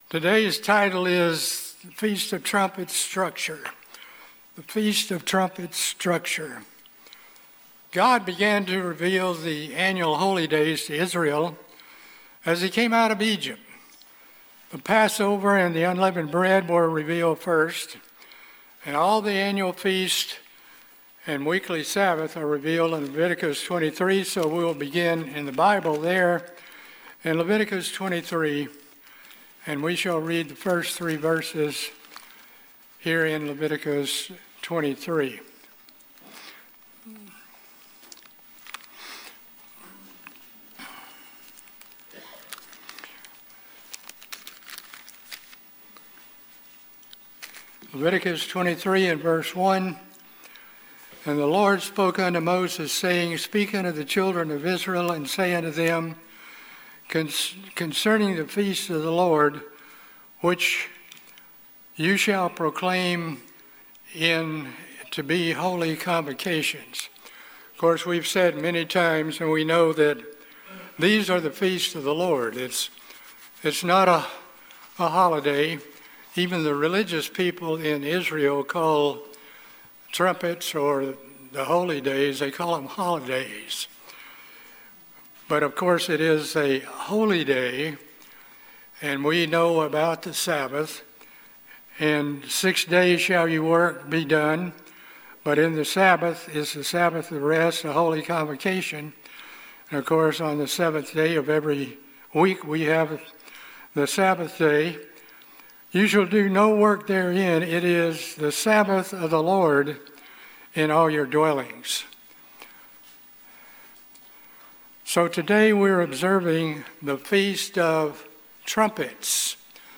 This sermon discusses some of the major historical aspects of the Feast of Trumpets from the time they were revealed to Israel to the present day. The duties of the watchman and how believers should respond to the message are noted along with the relationships of events such as sealing the 144,000, the opening of the seals, the angels sounding the trumpets---the events surrounding the seventh trumpet including the resurrection and the seven vials of wrath.